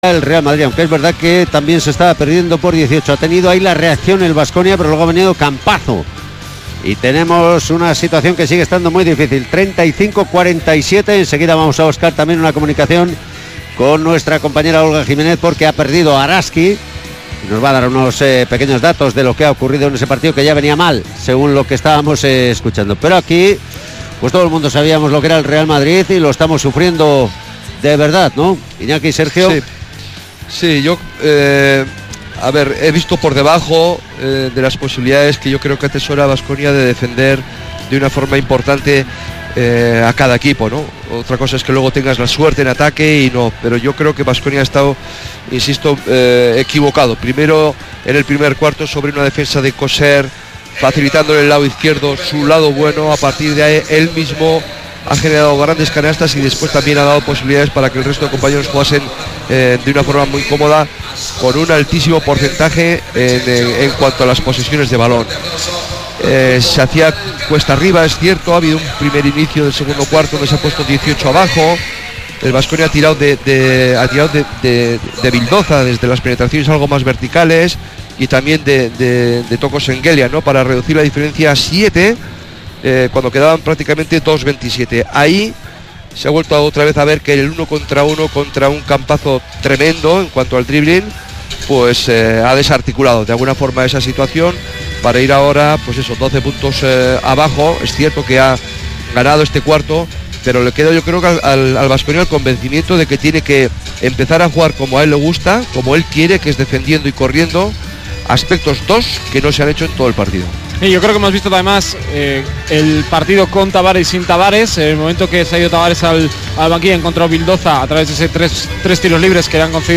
Kirolbet Baskonia-Real Madrid jornada 14 ACB 2018-19 retransmisión Radio Vitoria(segunda parte)